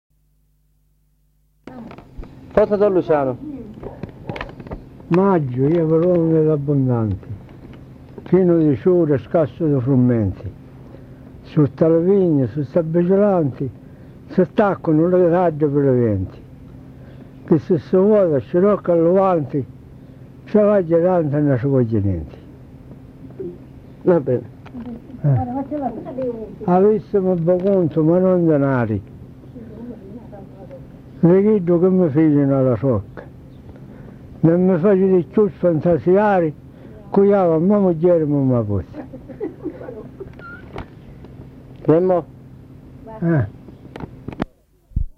Fra il 1994 e il 1997 sono state raccolte le memorie di fatti, racconti, preghiere che si tramandavano oralmente: di seguito pubblichiamo due testimonianze rilevate nel villaggio Massa San Nicola.